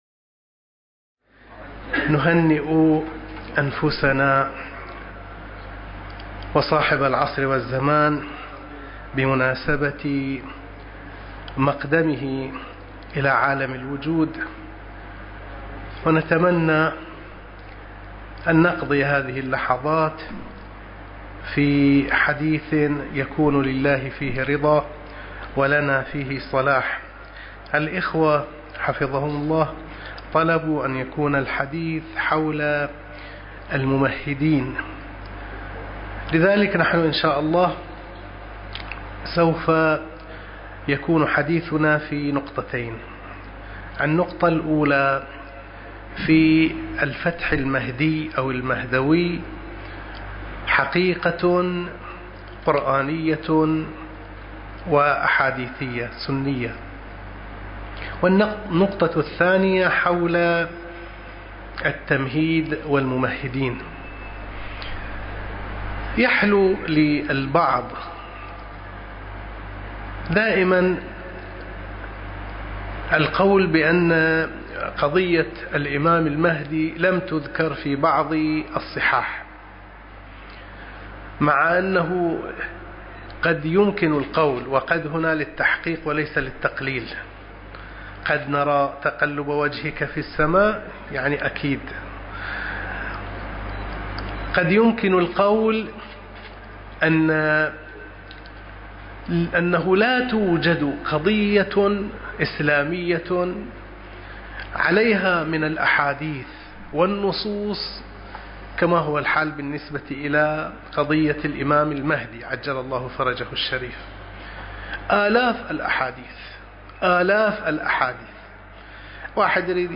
المكان: البحرين- بني جمرة- مأتم الغرب